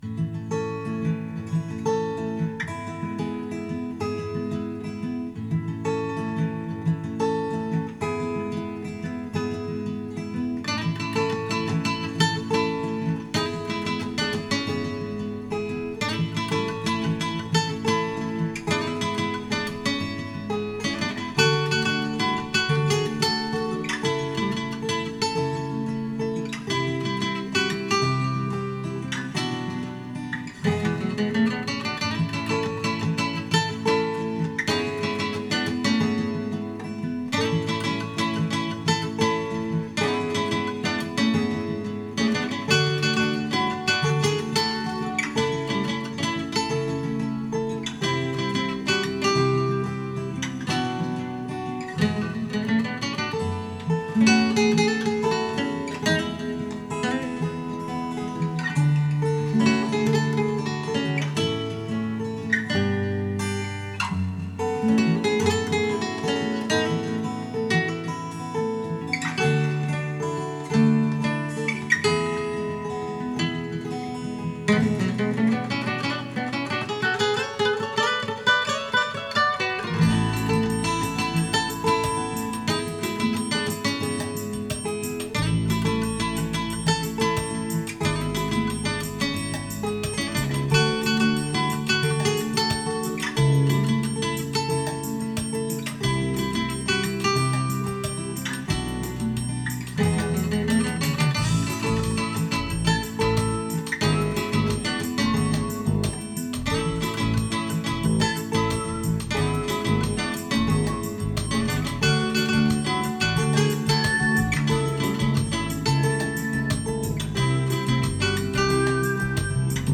X-Yステレオマイクロホン AT9943
CDの音楽をスピーカーから再生し、
DR-100で96kHz24bitと48kHz16bitで録音しました。
スピーカーは、DS-500とYST-SW50です。